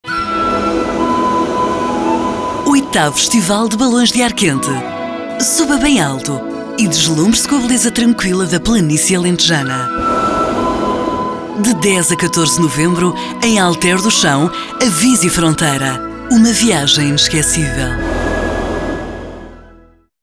活动宣传【活力大气】